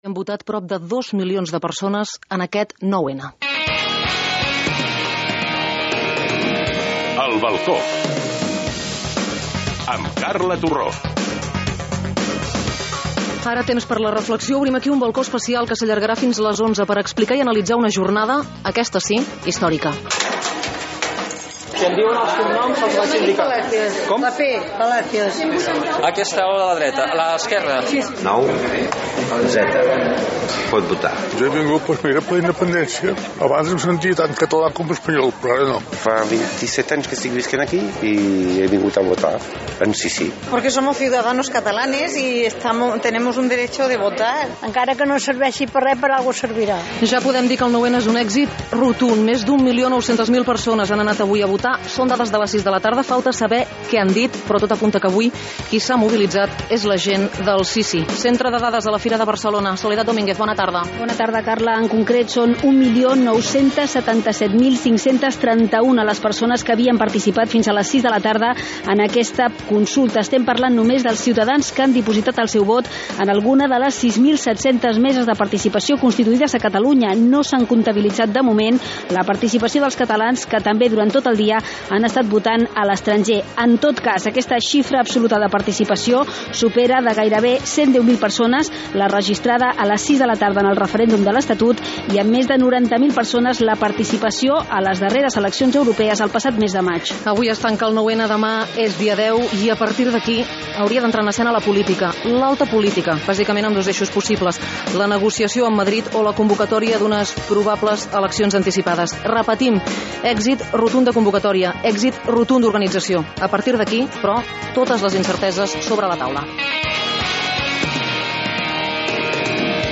Careta del programa, resultat de la participació en la votació de la consulta participativa sobre l’estatus polític de Catalunya (9N) i valoració dels integrants de la tertúlia política
Informatiu
FM